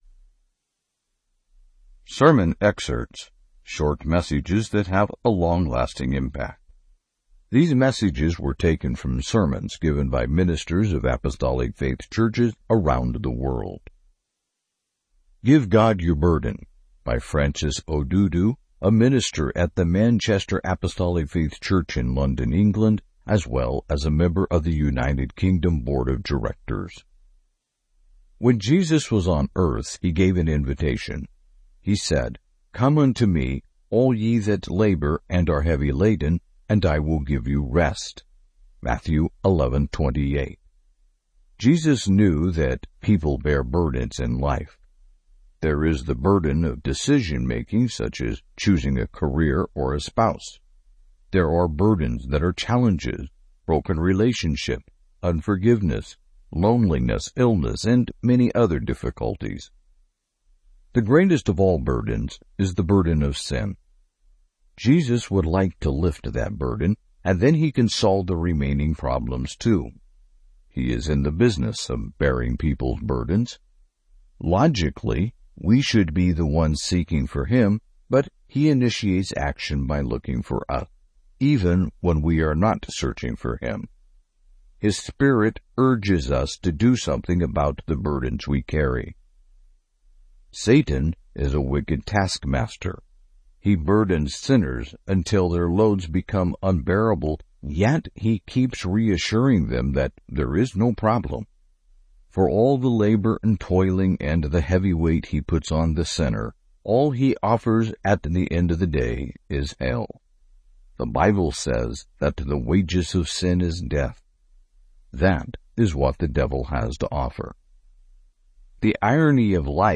Sermon Excerpts
These messages were taken from sermons given by ministers of Apostolic Faith Churches around the world.